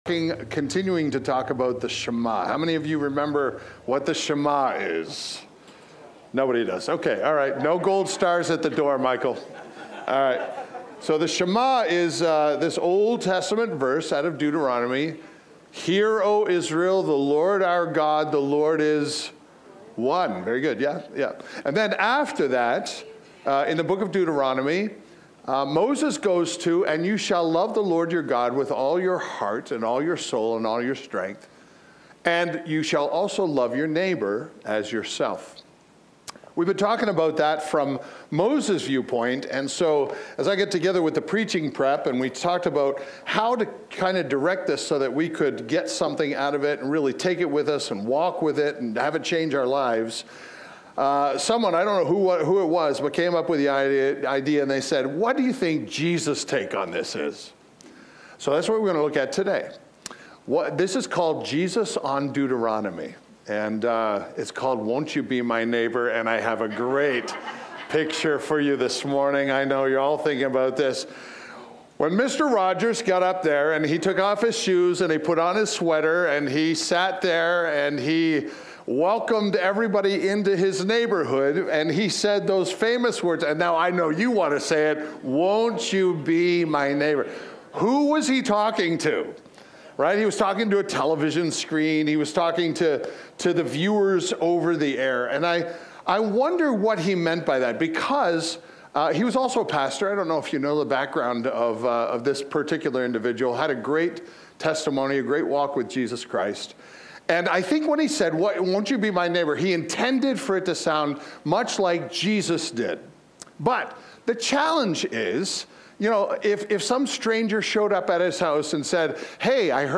Village Church East: Sermons Jesus on Deuteronomy Part 1: Will You Be My Neighbor?